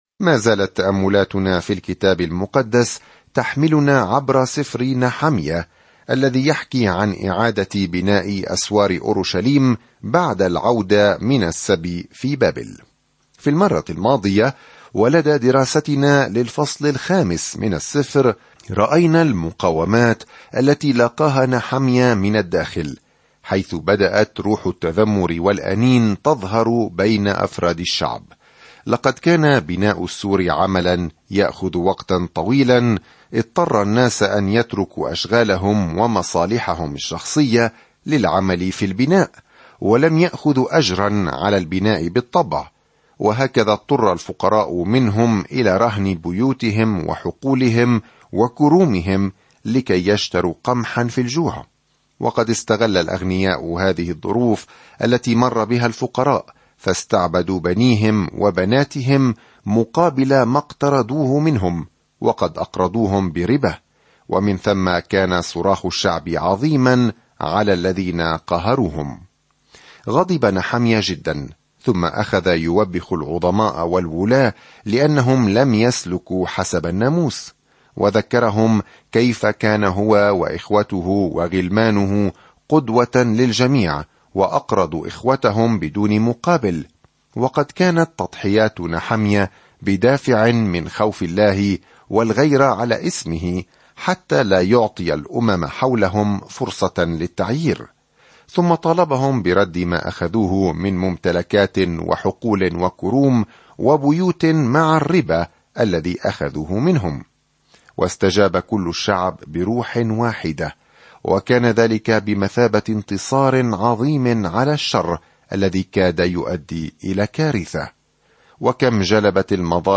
الكلمة نَحَمْيَا 6 يوم 7 ابدأ هذه الخطة يوم 9 عن هذه الخطة عندما تعود إسرائيل إلى أرضها، تصبح القدس في حالة سيئة؛ رجل عادي، نحميا، يعيد بناء السور حول المدينة في هذا الكتاب التاريخي الأخير. سافر يوميًا عبر نحميا وأنت تستمع إلى الدراسة الصوتية وتقرأ آيات مختارة من كلمة الله.